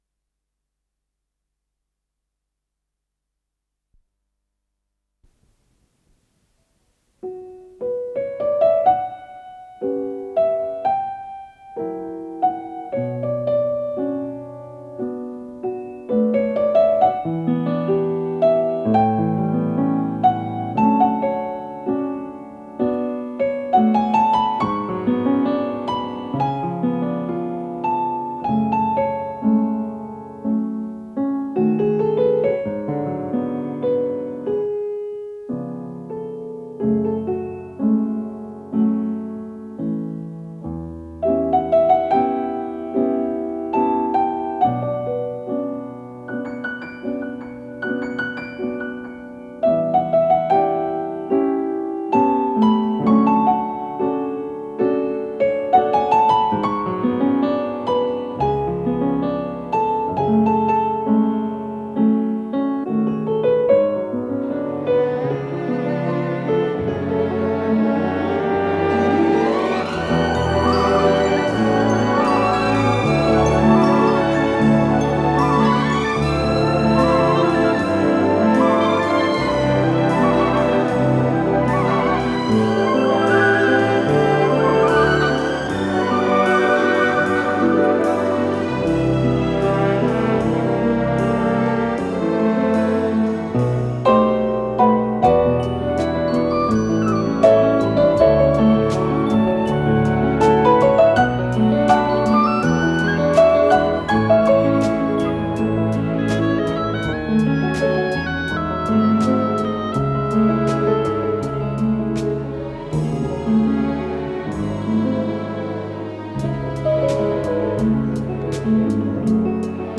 с профессиональной магнитной ленты
фортепиано
Скорость ленты38 см/с